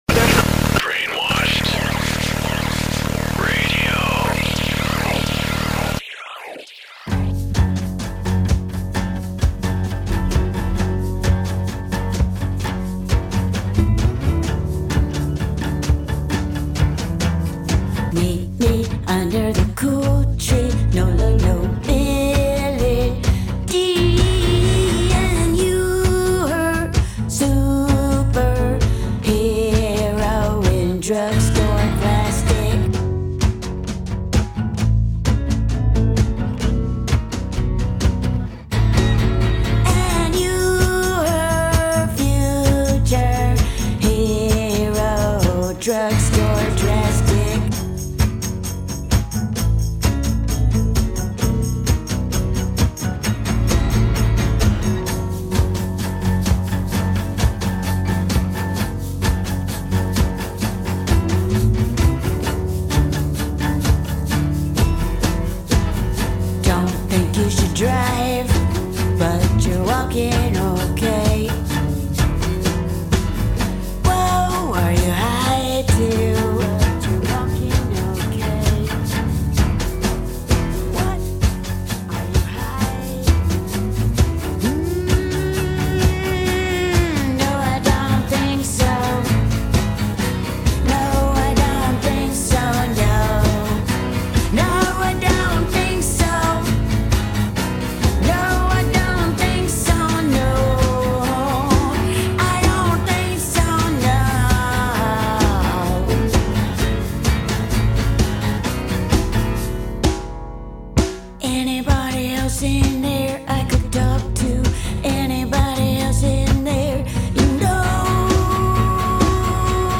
Eclectic Music